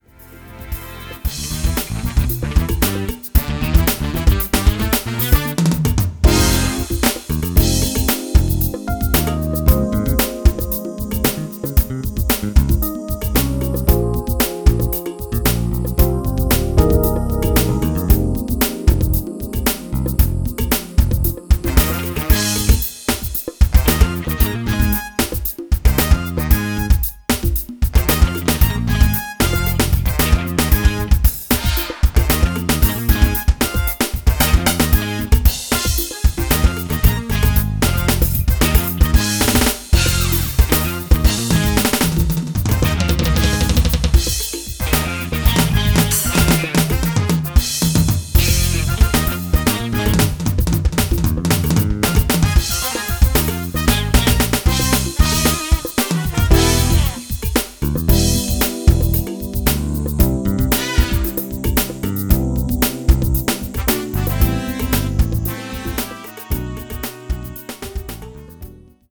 Live horn section
Guitar included.
Key of E Minor